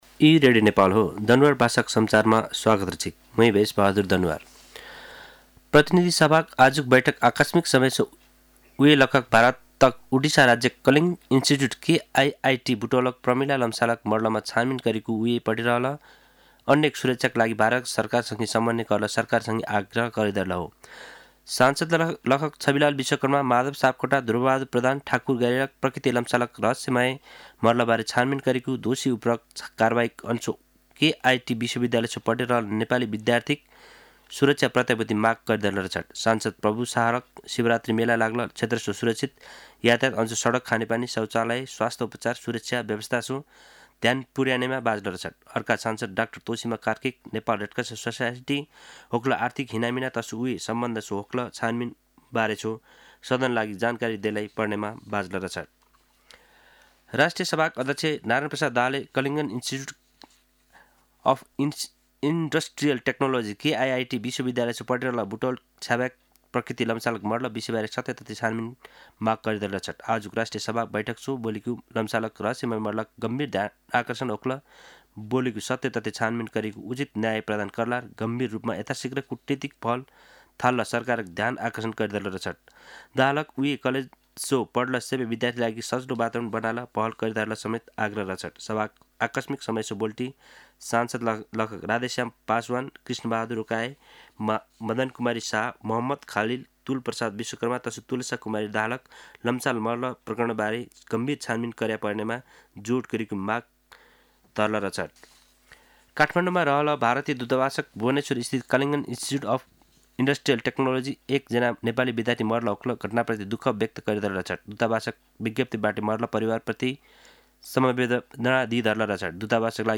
दनुवार भाषामा समाचार : ७ फागुन , २०८१
danuwar-news-3.mp3